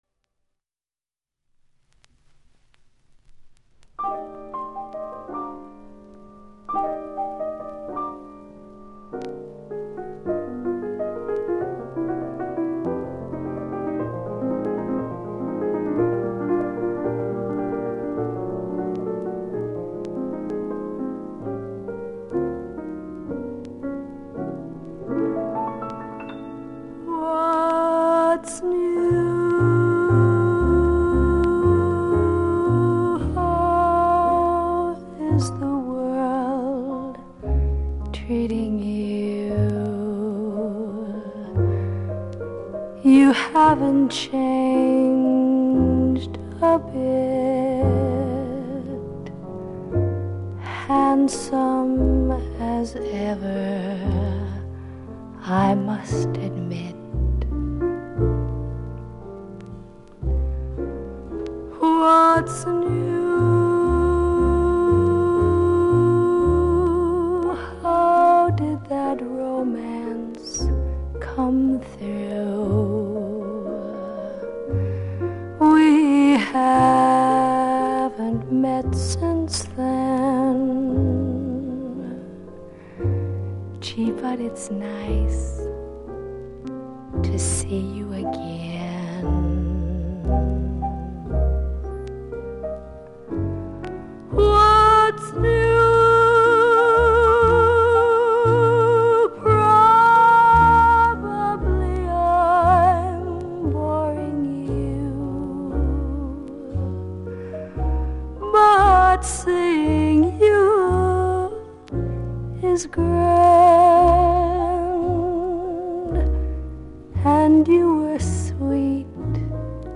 わずかに音われするような気がします。（ステレオ針にて試聴）
モノ針ですとノイズも減ります。
他の部分はほぼ問題なくいい音だと思います。